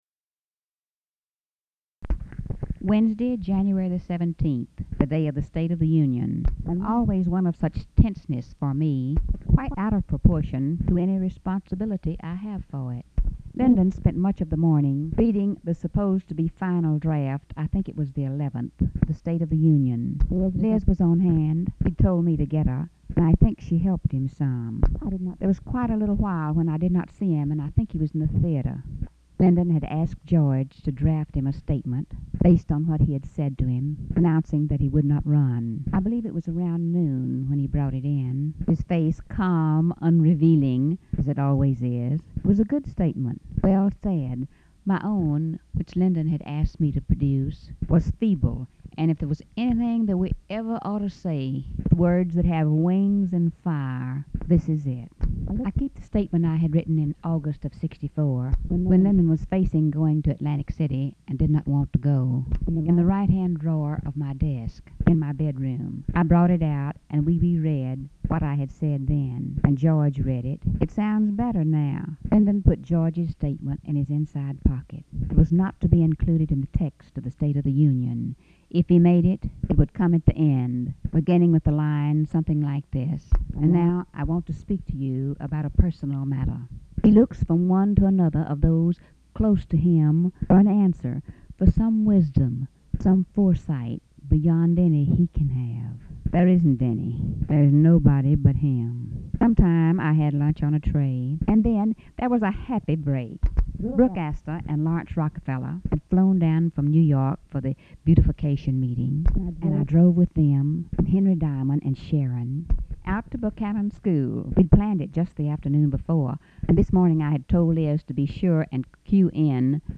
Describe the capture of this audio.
Location Detail White House, Washington, DC